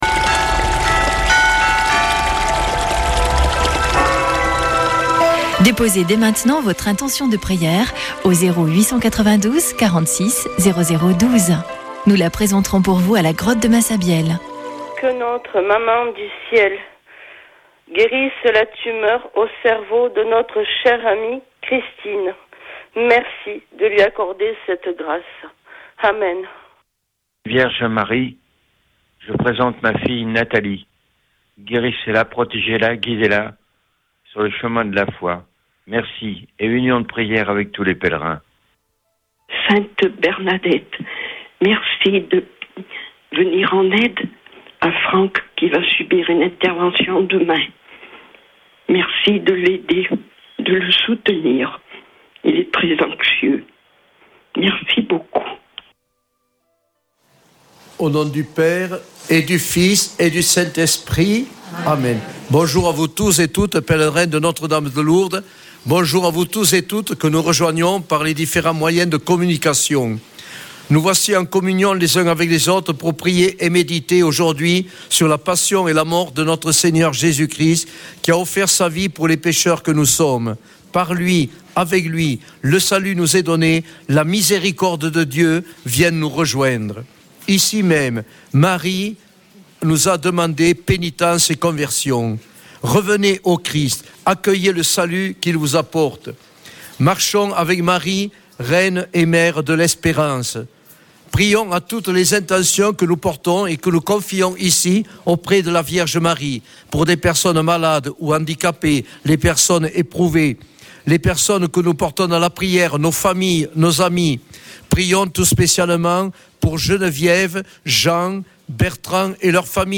Accueil \ Emissions \ Foi \ Prière et Célébration \ Chapelet de Lourdes \ Chapelet de Lourdes du 30 sept.
Une émission présentée par Chapelains de Lourdes